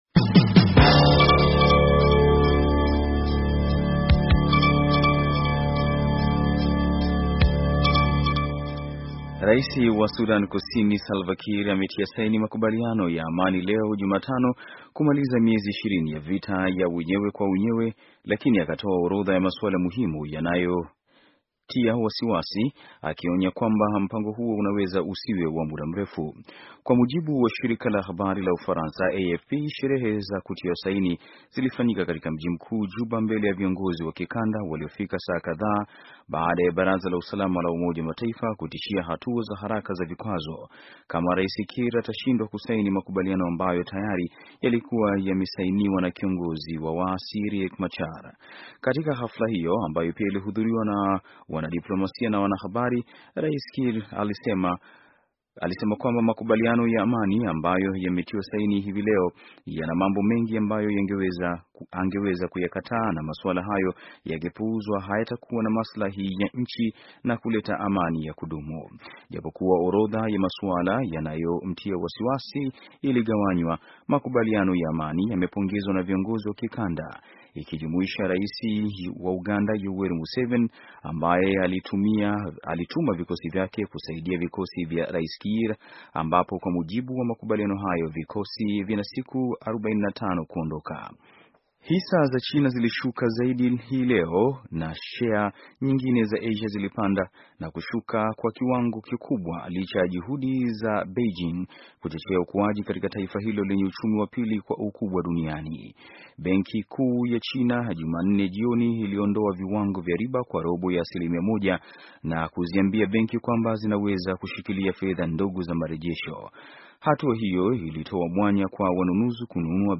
Taarifa ya habari - 6:49